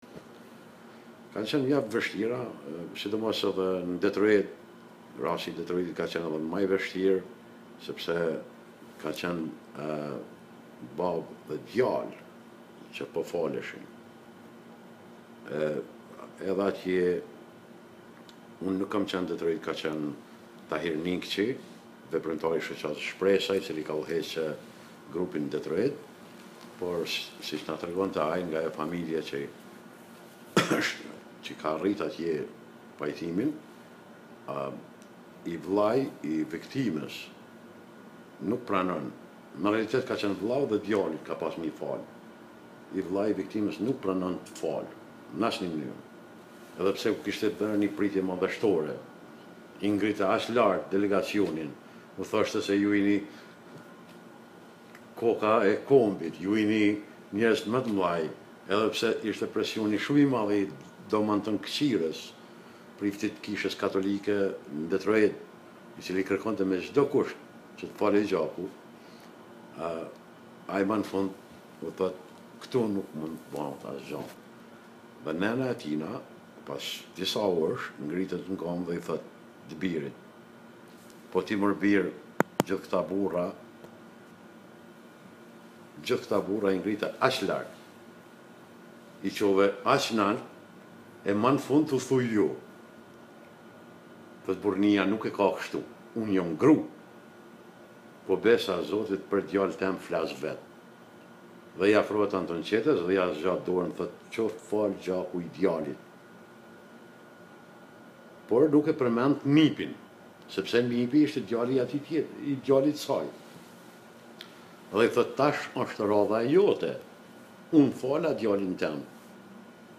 The audio interview section